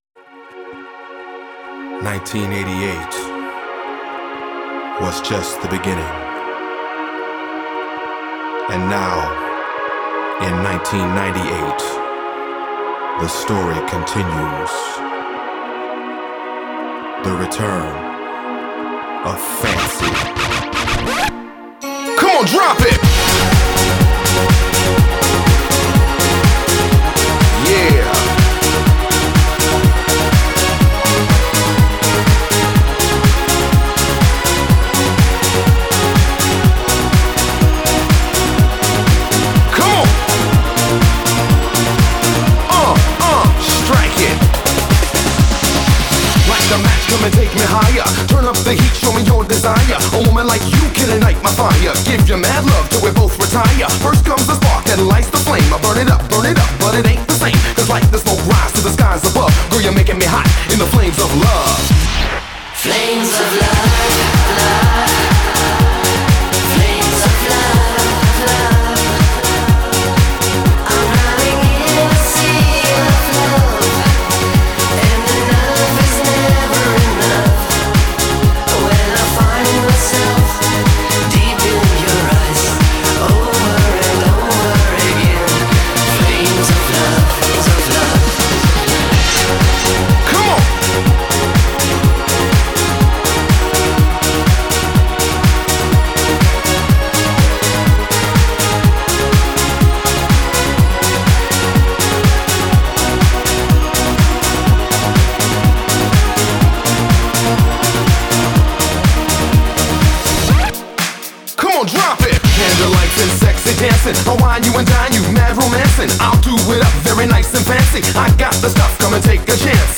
Категория: Хиты 80-х